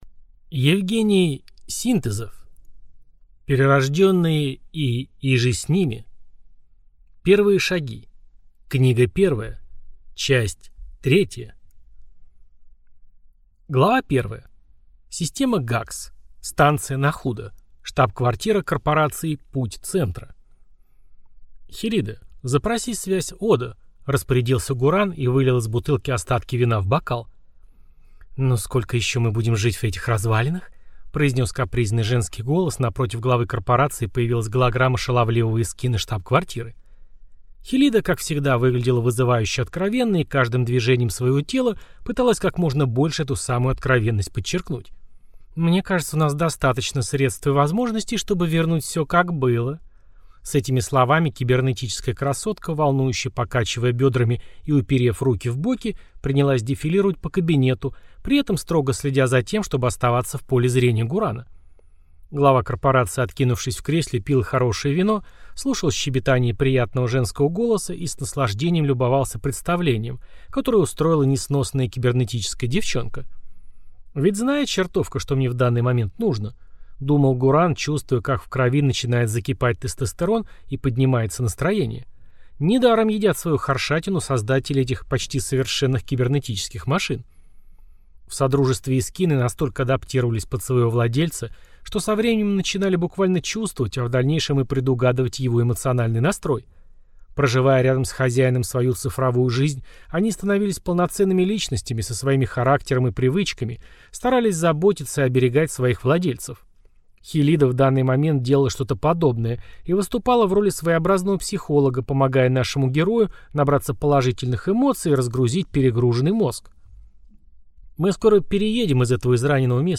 Аудиокнига Перерожденные и иже с ними… Первые шаги. Книга первая. Часть третья | Библиотека аудиокниг